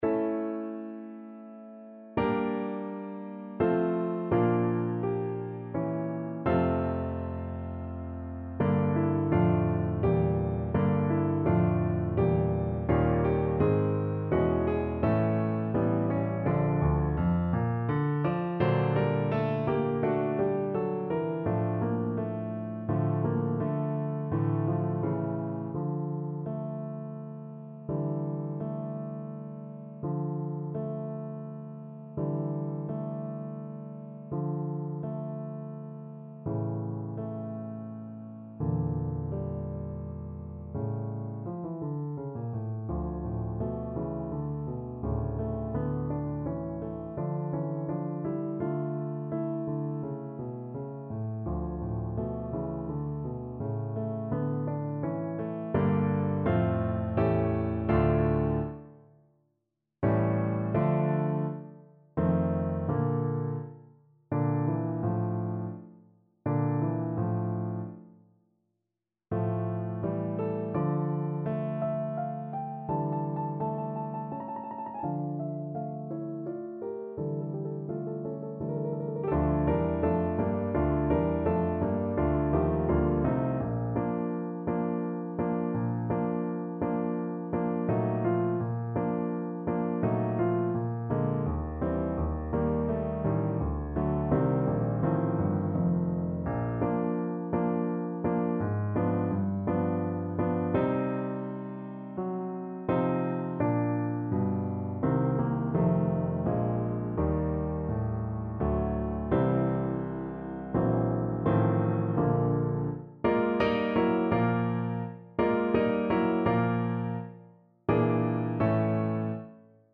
Andante =84